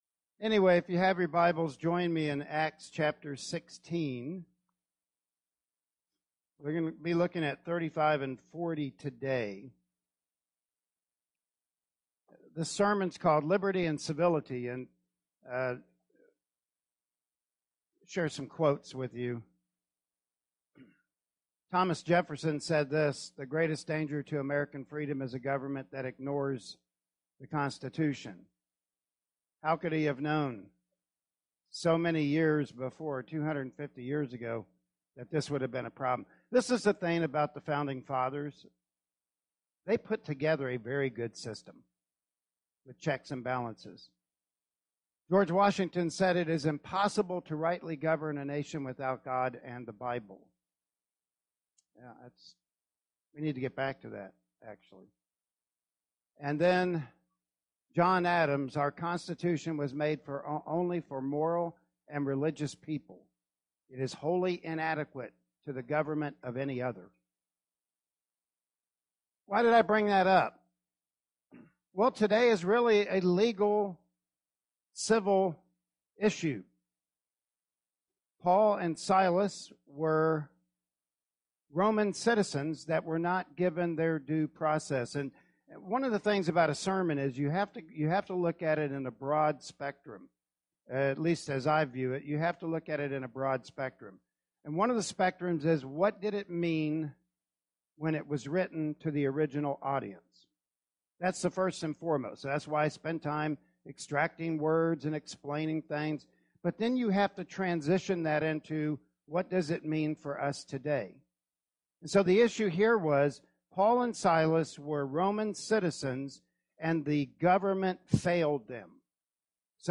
Acts 16 Passage: Acts 16:35-40 Service Type: Sunday Morning Worship Service Topics